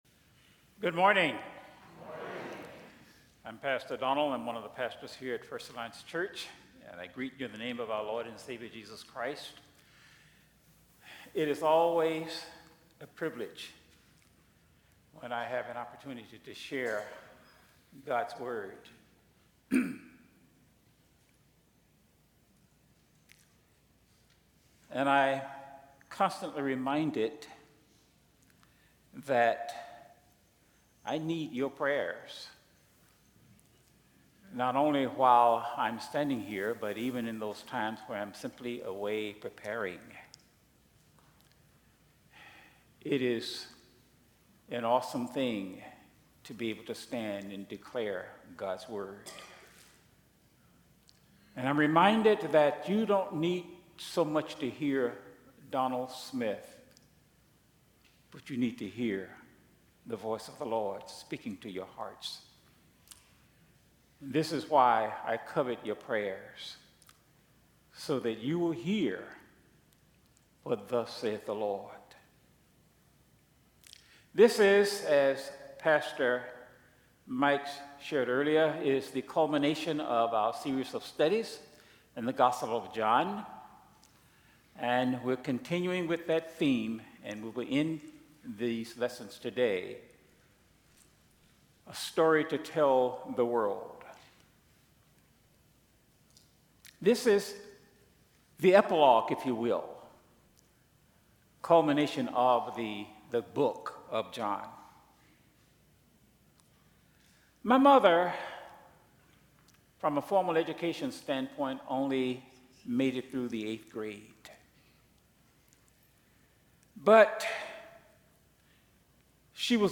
Sermons | First Alliance Church Toledo